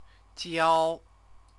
chinese_characters_jiao-3_jiao-3.mp3